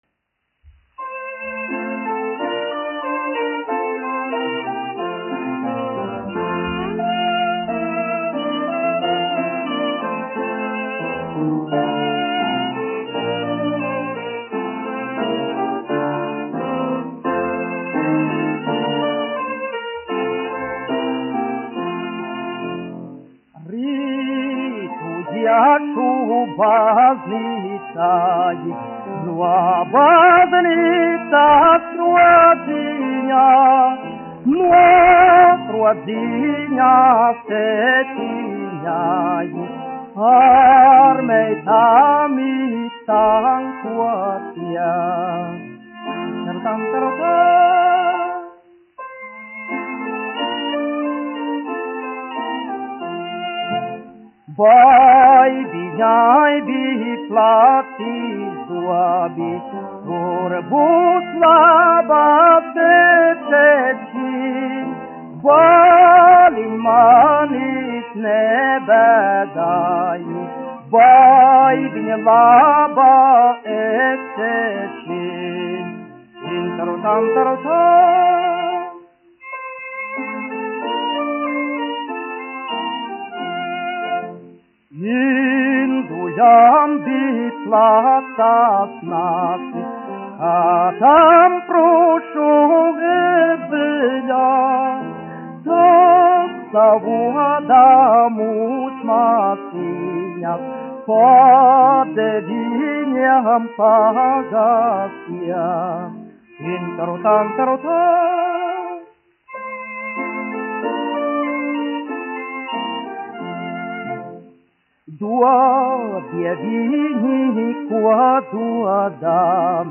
1 skpl. : analogs, 78 apgr/min, mono ; 25 cm
Dziesmas (augsta balss)
Latvijas vēsturiskie šellaka skaņuplašu ieraksti (Kolekcija)